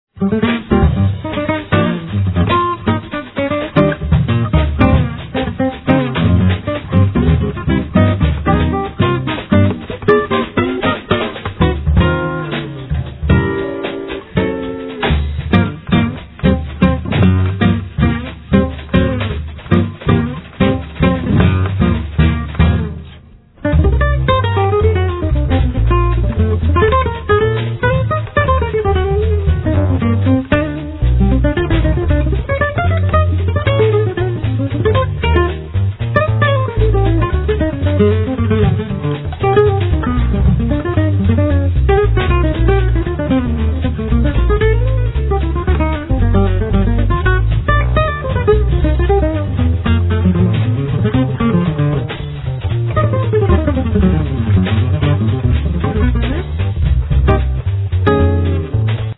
Carlos do Carmo   Guitar
Acoustic bass
Accordion
Drums